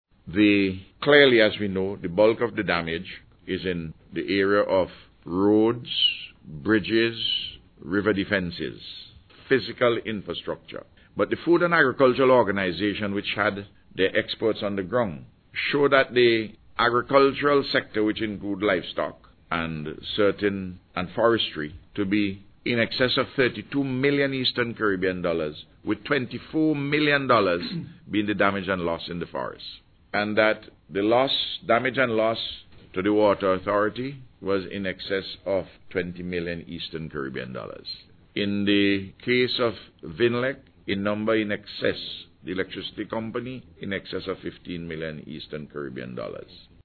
He presented the figure at a news conference yesterday which coincided with a visit from the French Embassy and the Regional Council of Martinique, including its President Serge Letchemy.